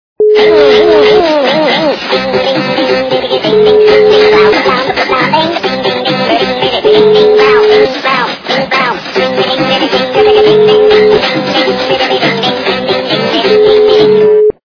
- Remix
При заказе вы получаете реалтон без искажений.